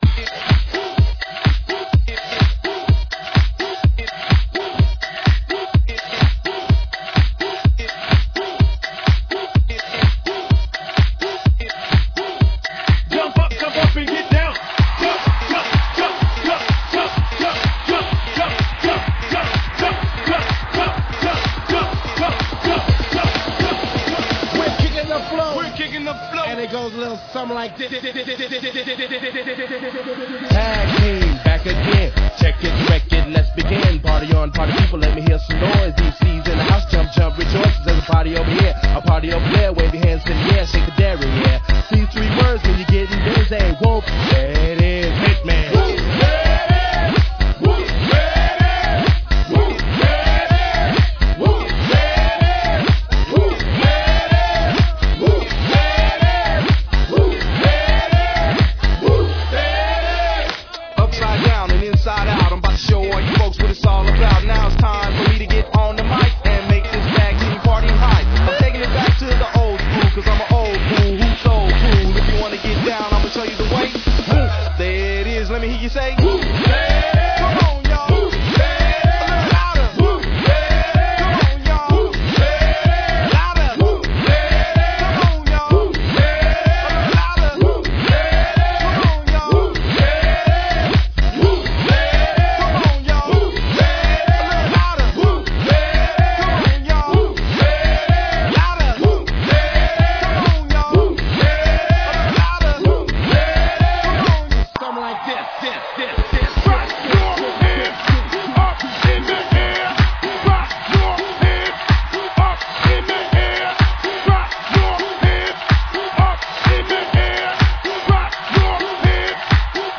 Hard hitting, club banging, dirty electro house!!
Classic track meets popular electro style!
Dancefloor tested & approved!